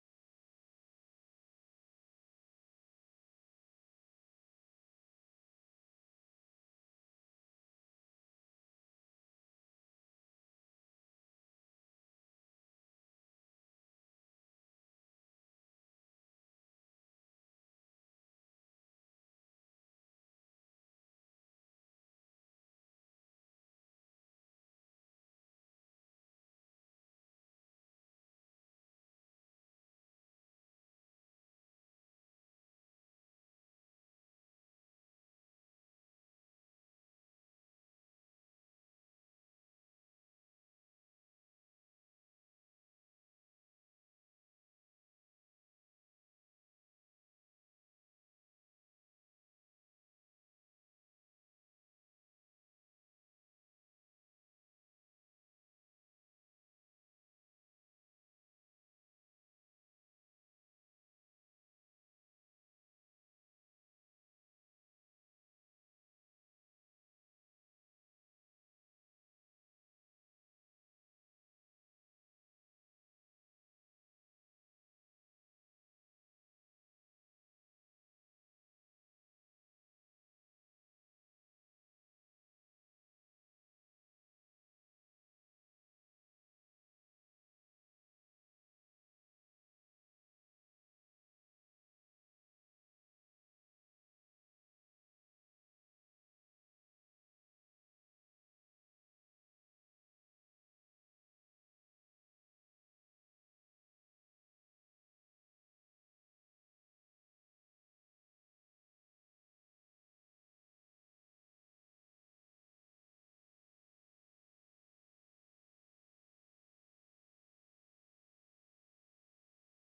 Sermon looking at the four gifts Christ Made available to us after his death A. God takes away the sins of the world B. Healing of Infirmities C. Christ Empathizes with Us. D. Way into the Holiest of all is Opened.